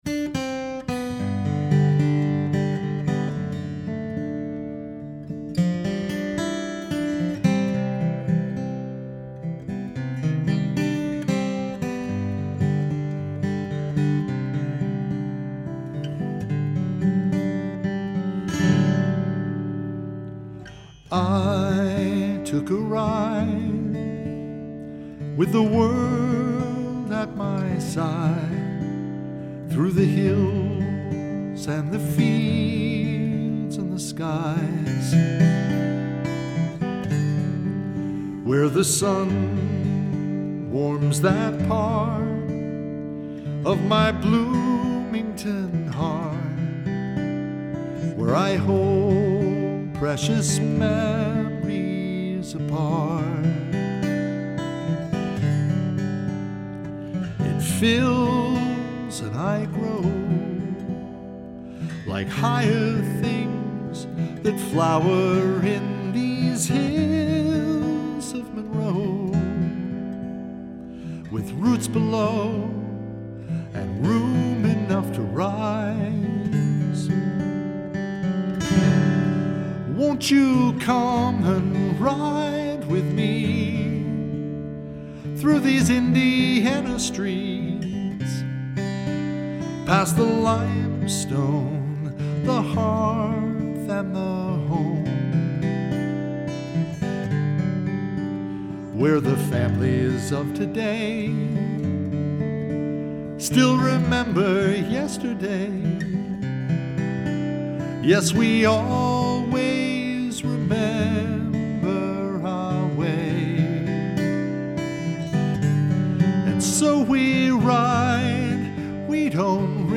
Piano/Voice/Guitar Sheet Music -